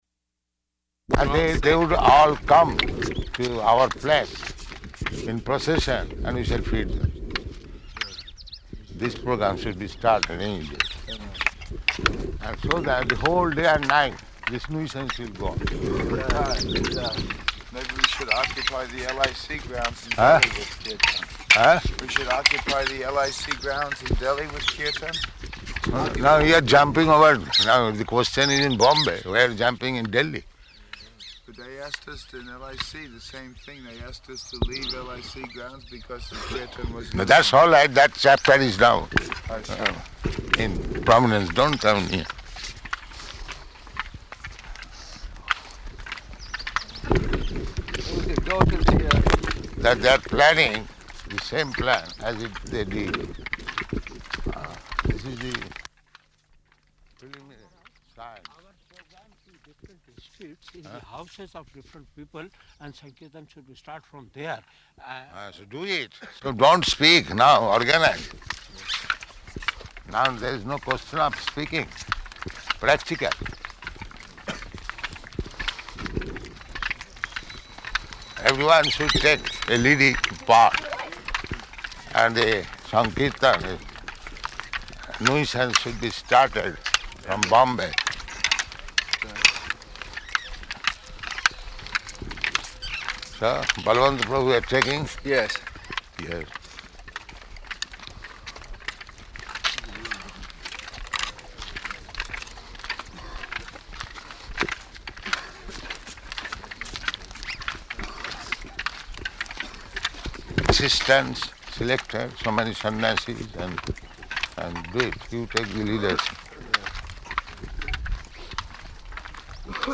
Morning Walk --:-- --:-- Type: Walk Dated: March 17th 1974 Location: Vṛndāvana Audio file: 740317MW.VRN.mp3 Prabhupāda: ...and they would all come to our place in procession, and we shall feed them.